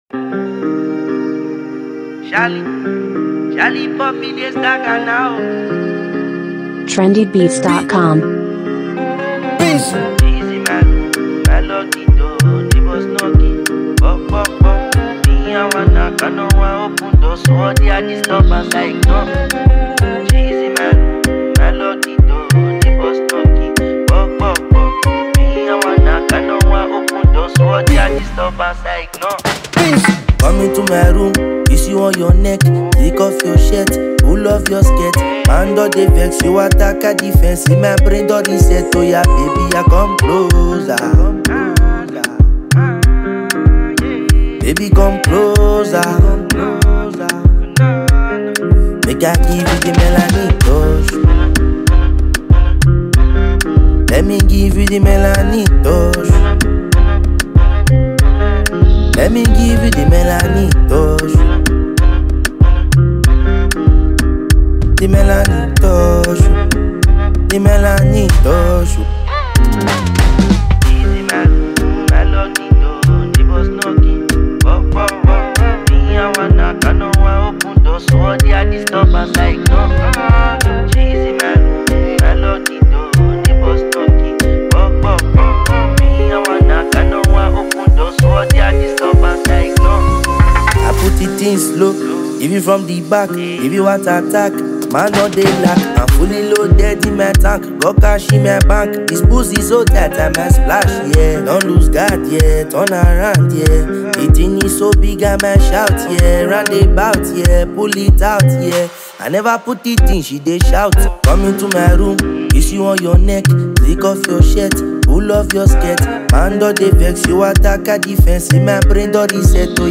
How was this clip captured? studio album